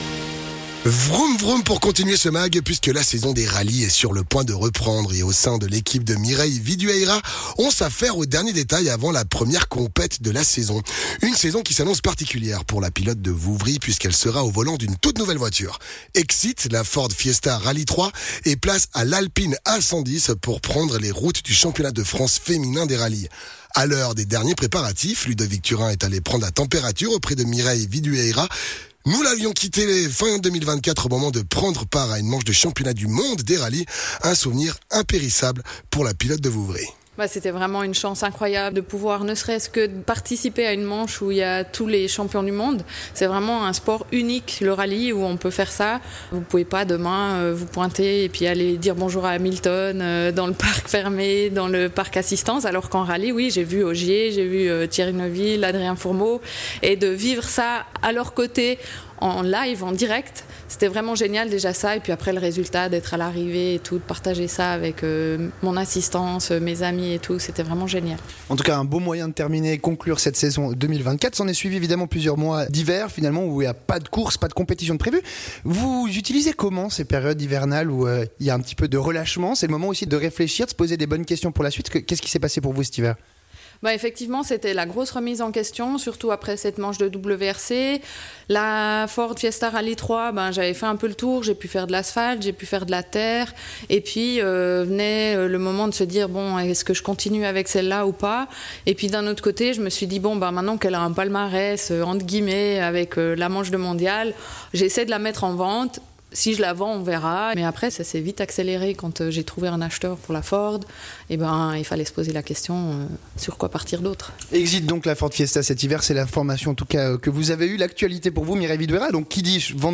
pilote de Rallye